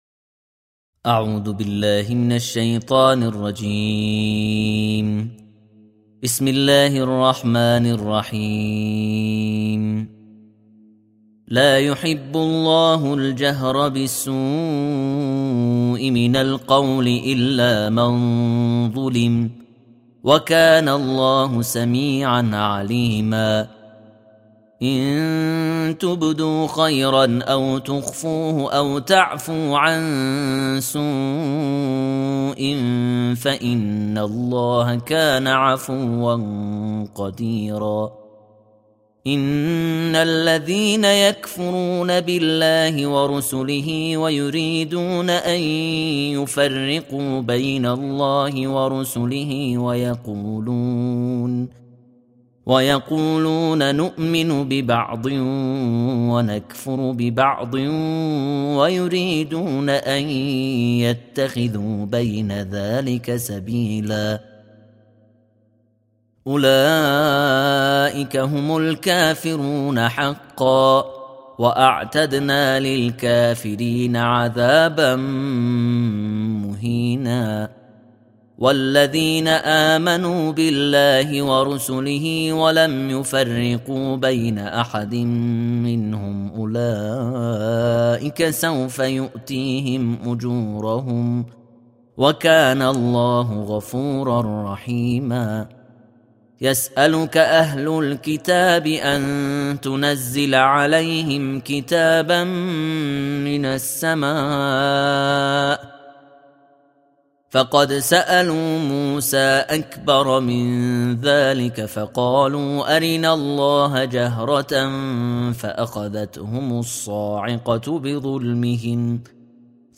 تلاوت جزء ششم قرآن